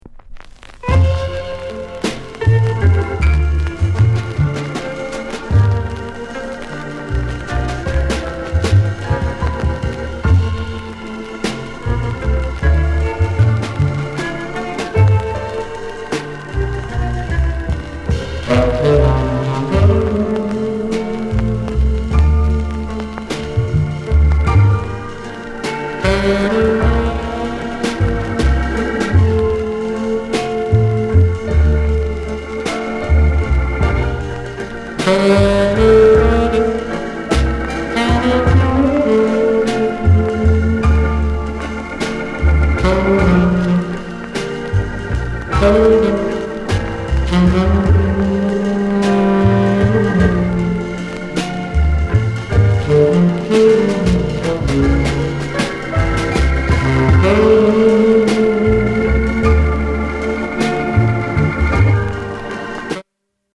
SOUND CONDITION A SIDE VG-(OK)